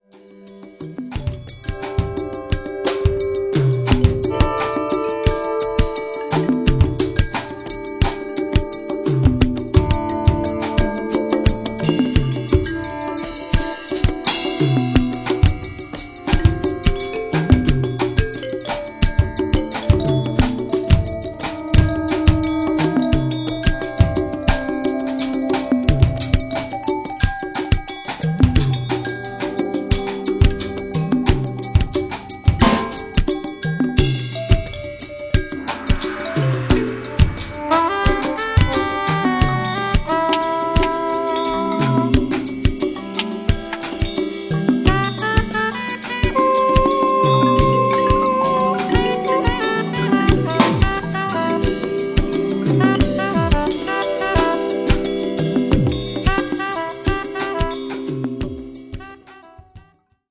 In short, it grooves as it moves.